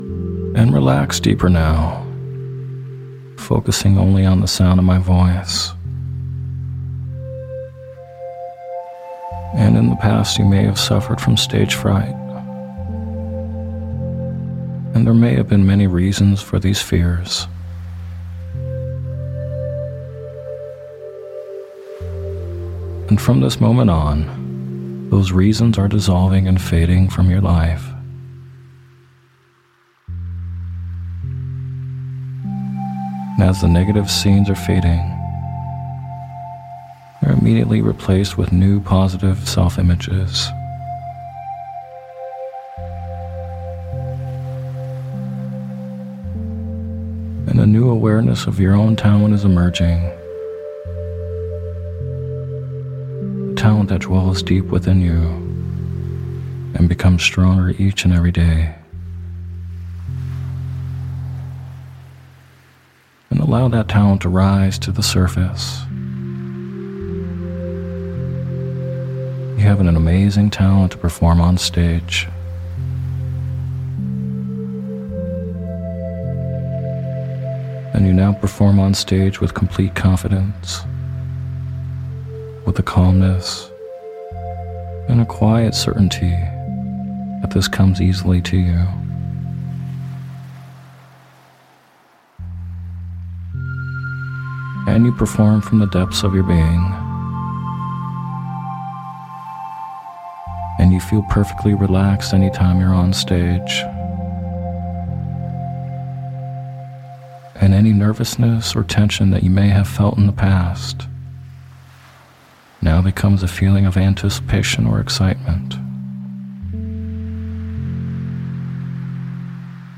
In this guided meditation or sleep hypnosis audio you’ll be given positive suggestions and a visualization for getting over stage fright.